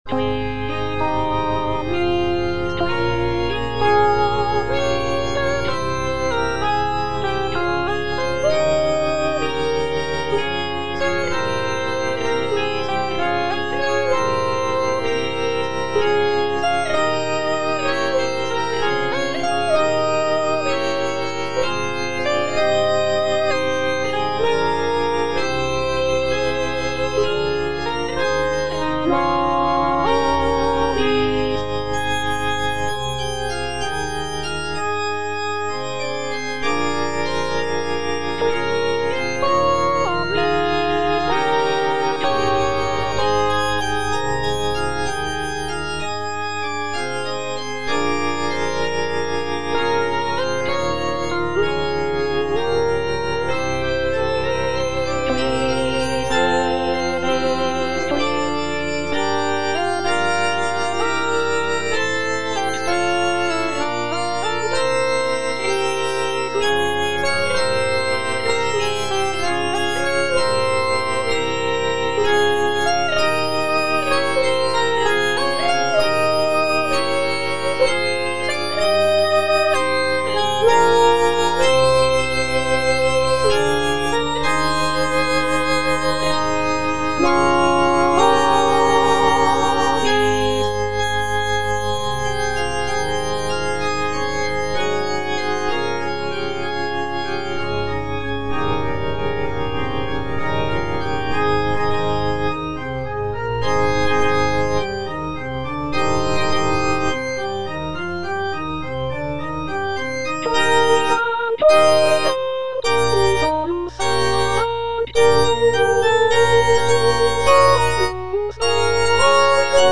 G. FAURÉ, A. MESSAGER - MESSE DES PÊCHEURS DE VILLERVILLE Qui tollis (All voices) Ads stop: auto-stop
The composition is a short and simple mass setting, featuring delicate melodies and lush harmonies.